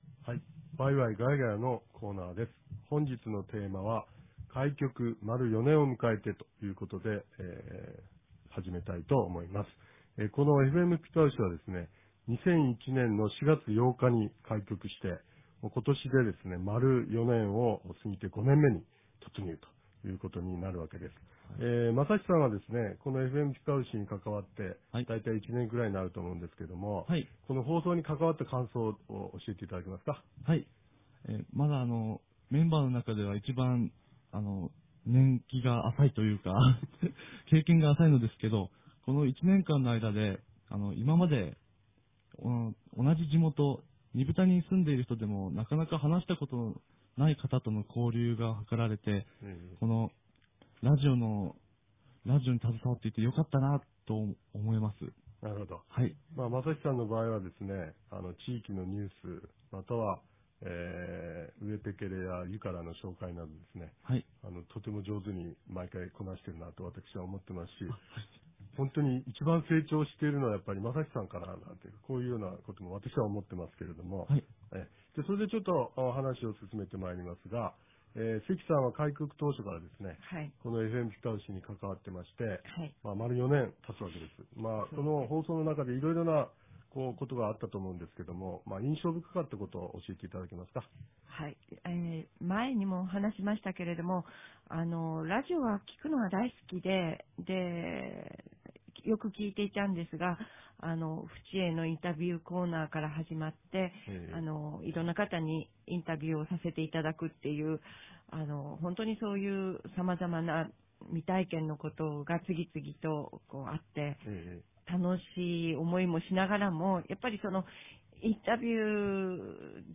■二風谷のスタジオから「わいわいがやがや」